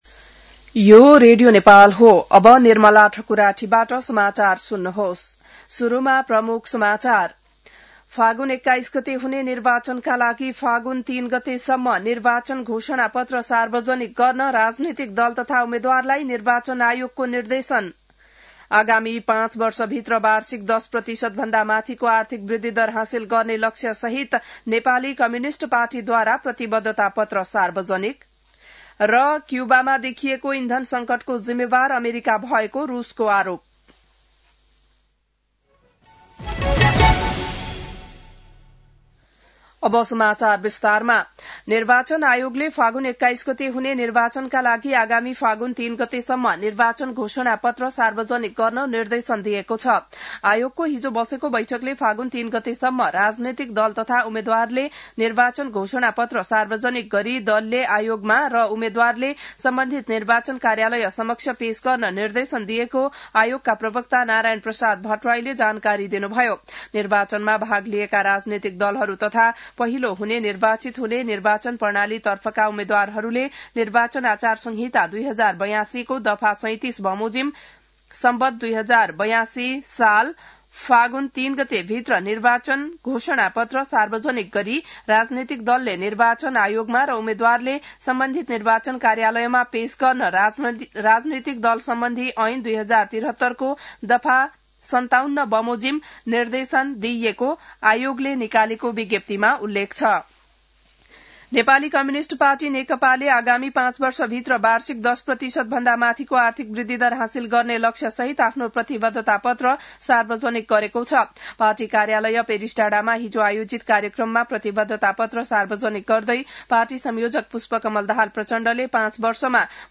बिहान ९ बजेको नेपाली समाचार : २८ माघ , २०८२